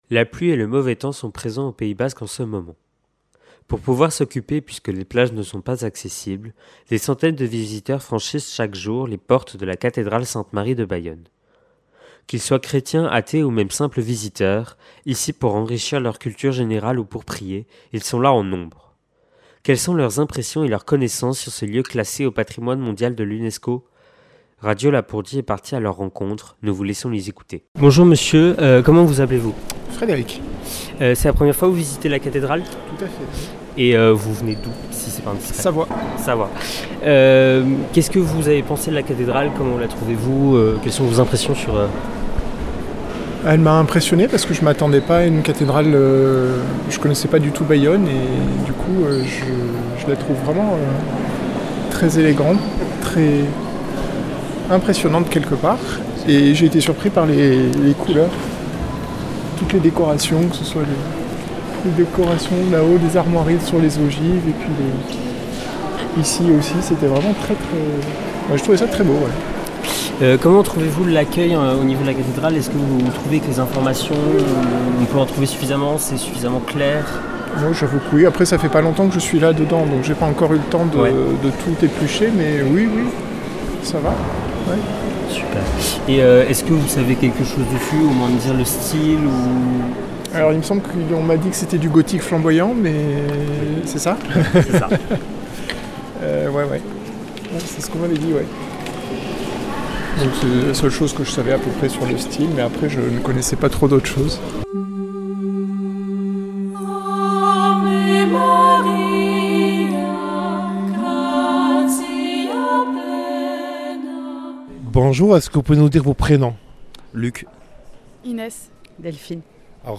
A la rencontre des visiteurs de la cathédrale Sainte-Marie de Bayonne
Accueil \ Emissions \ Infos \ Interviews et reportages \ A la rencontre des visiteurs de la cathédrale Sainte-Marie de (...)